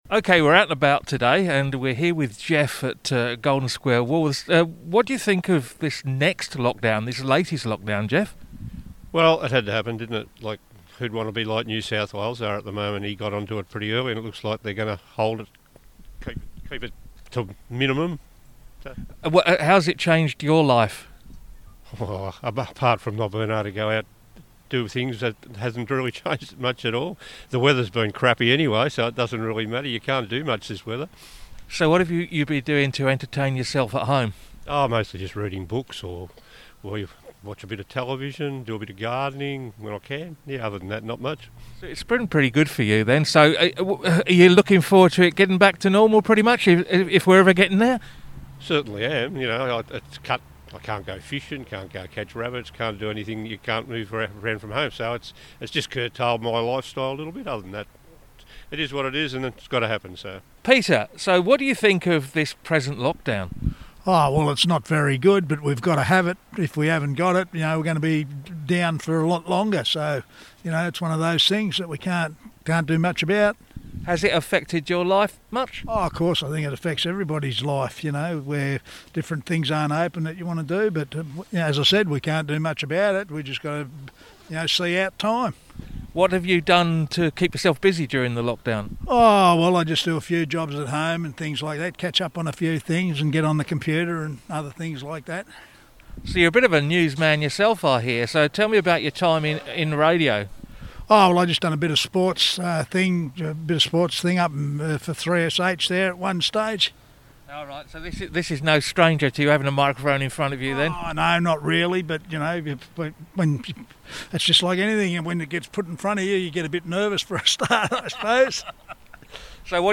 to have a chat on the streets to people and businesses to get their reaction to the latest lockdown...